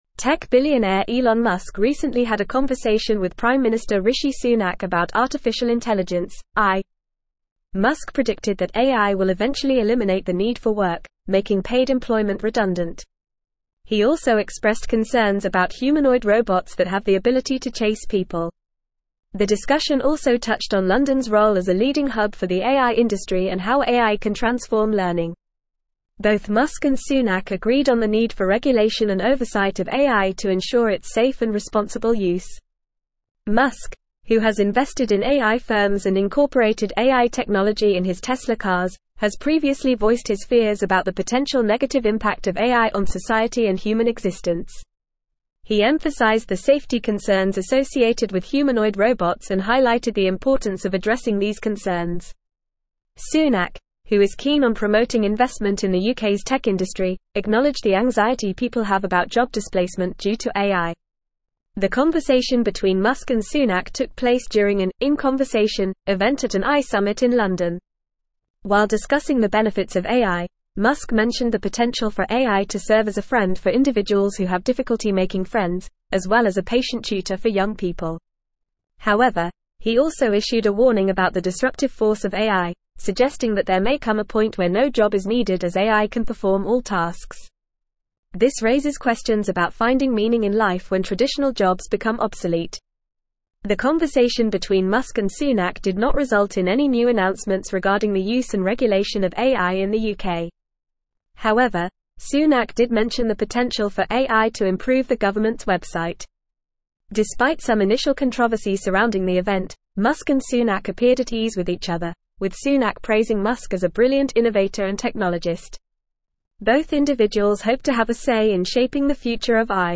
Fast
English-Newsroom-Advanced-FAST-Reading-Elon-Musk-and-Rishi-Sunak-discuss-AIs-impact.mp3